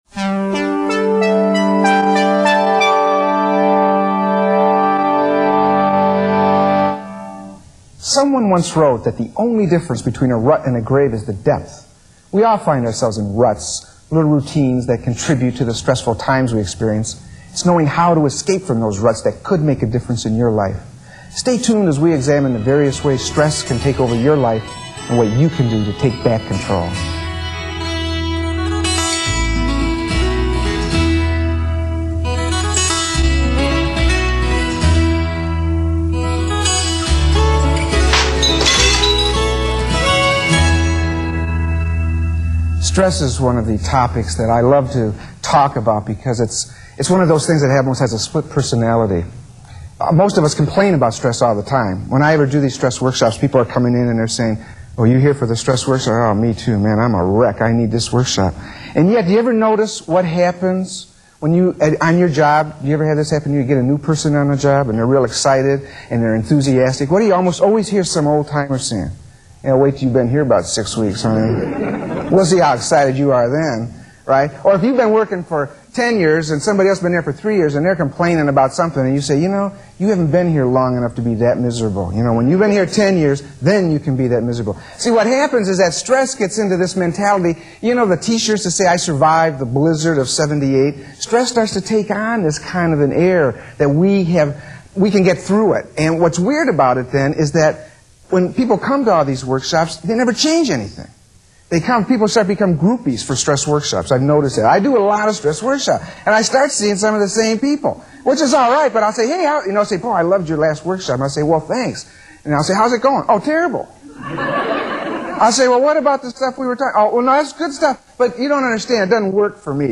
Don’t Manage Your Stress – Audio (Public Televison Lecture) (Digital)
This is the audio of one of the 6 part presentations I did for Public Television.